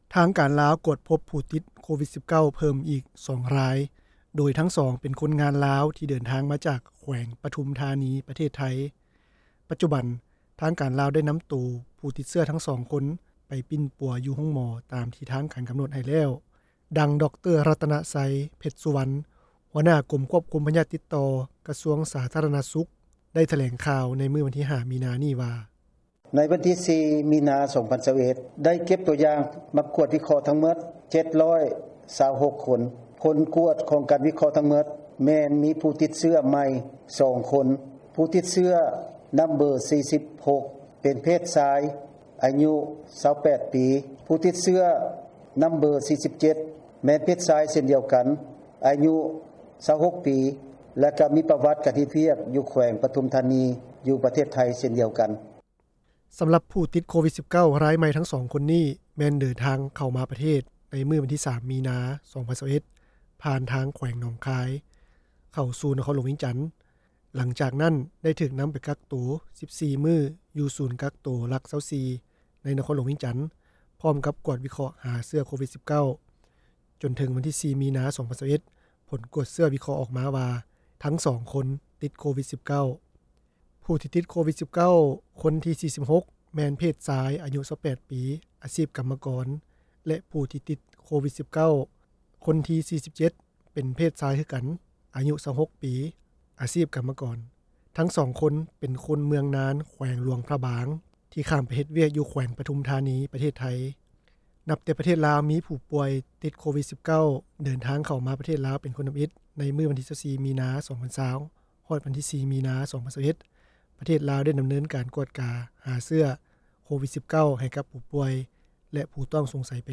ທາງການລາວ ພົບຜູ້ຕິດໂຄວິດ-19 ອີກ 2 ຄົນ – ຂ່າວລາວ ວິທຍຸເອເຊັຽເສຣີ ພາສາລາວ